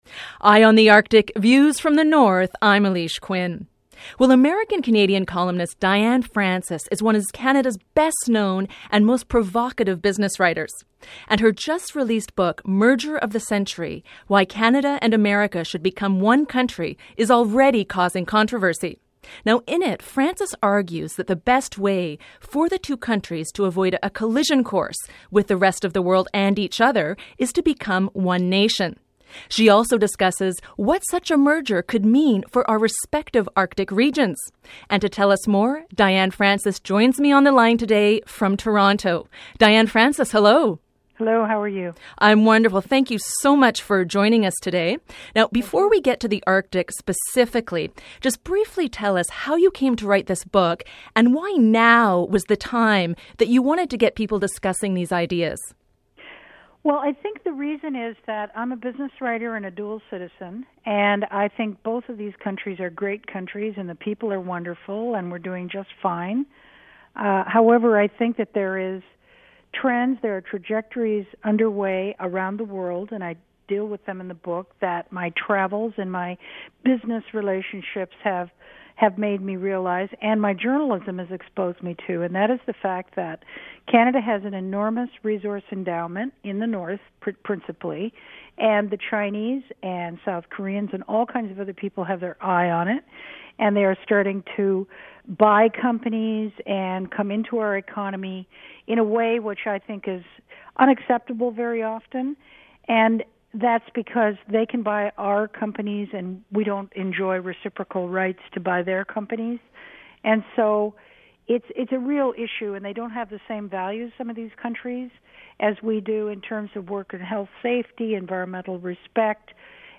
FEATURE INTERVIEW: Would a Canada-U.S. merger be good for the Arctic?
She warns that the cost of doing nothing could have serious consequences, especially for the Arctic. I spoke to Diane Francis earlier this week to find out why.